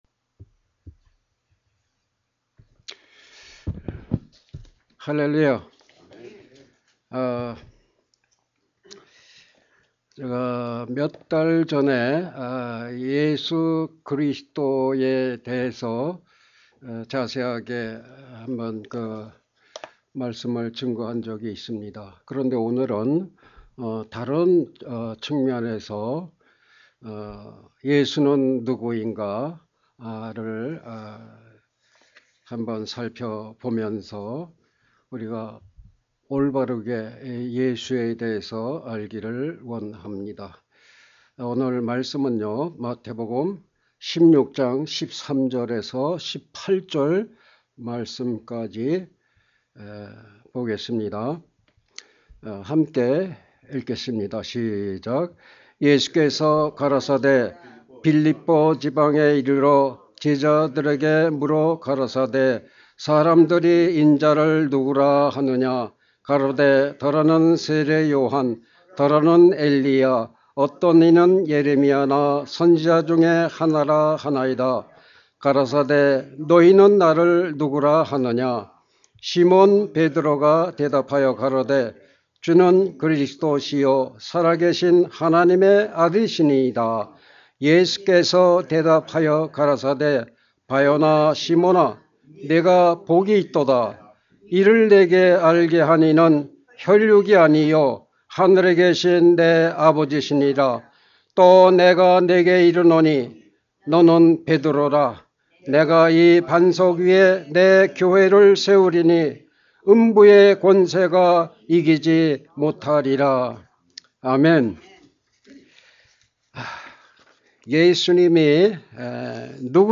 Sermon - 예수는 누구인가 Who is Jesus.